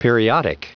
Prononciation du mot periodic en anglais (fichier audio)